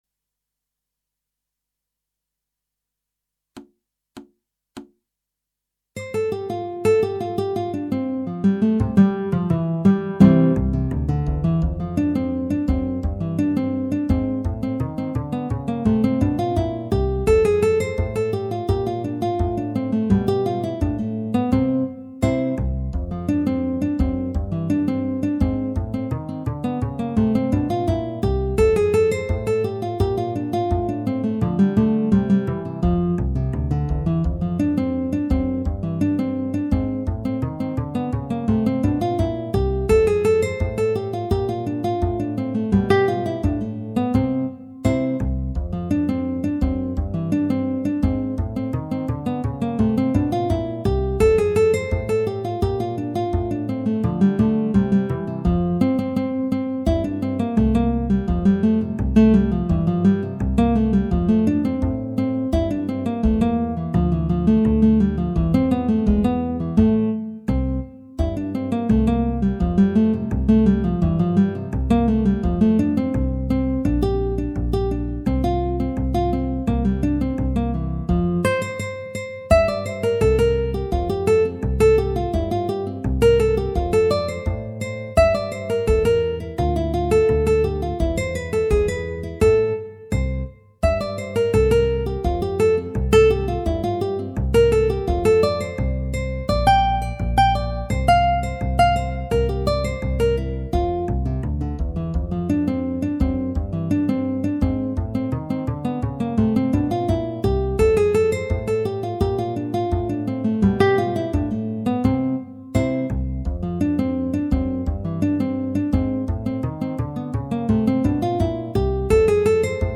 Guitar trio
minus Guitar 2